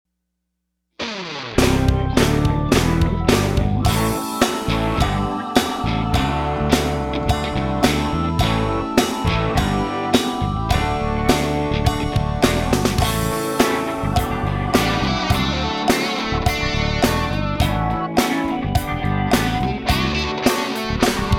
Listen to a sample of this instrumental song
Downloadable Instrumental Track